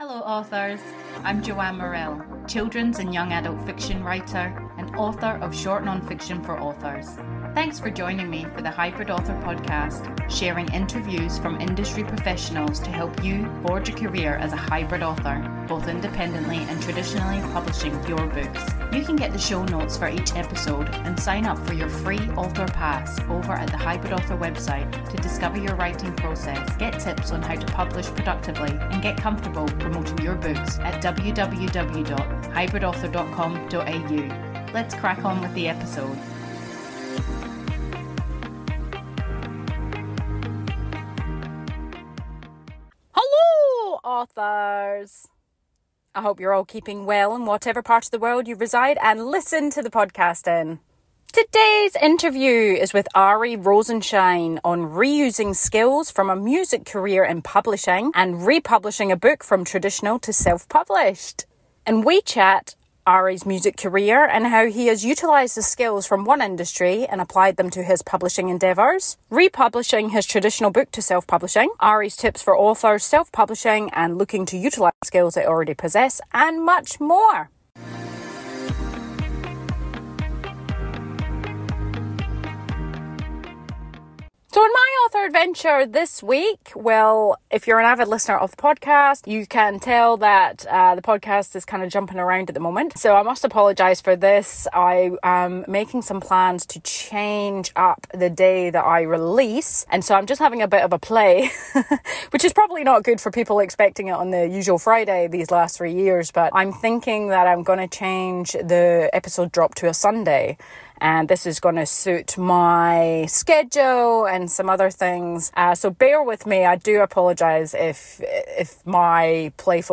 Interviews, for writers like you, with industry professionals on forging a career as a HYBRID Author: writing across genres, mixing writing styles, publishing all ways and getting comfortable promoting you and your books.